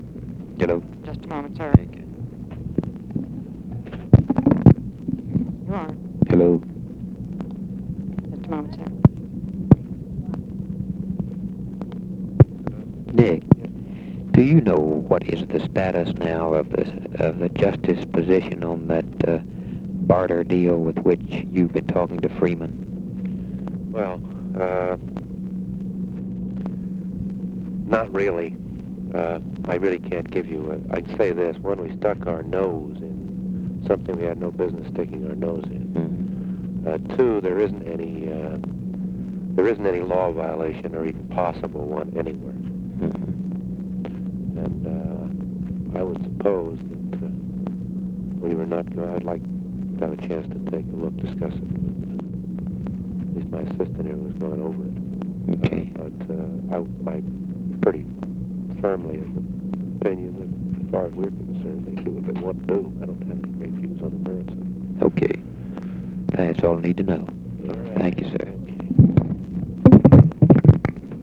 Conversation with NICHOLAS KATZENBACH and BILL MOYERS
Secret White House Tapes